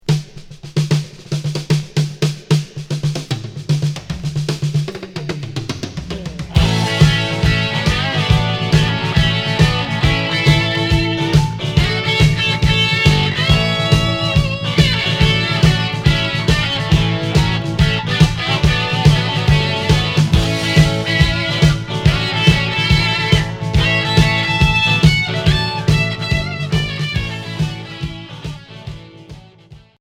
Rock blues